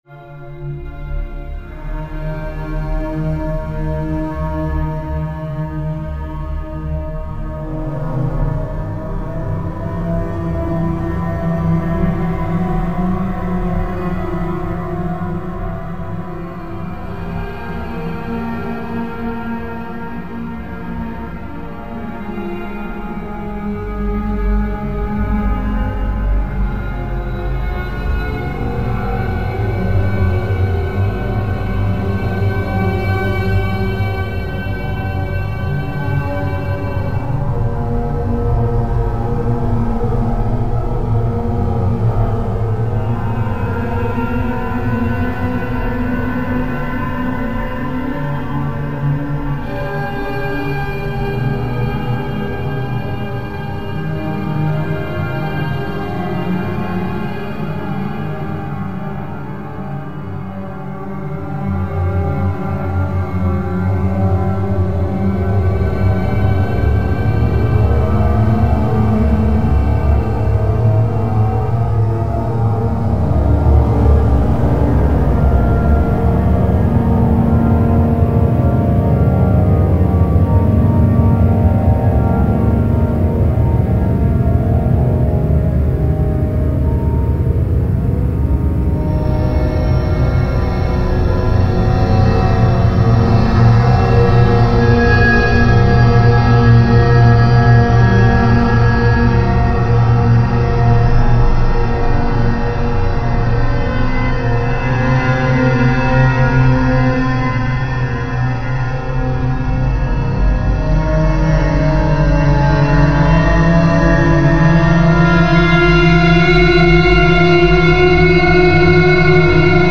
File under: Avantgarde / Experimental
a microtonal and minimalist homage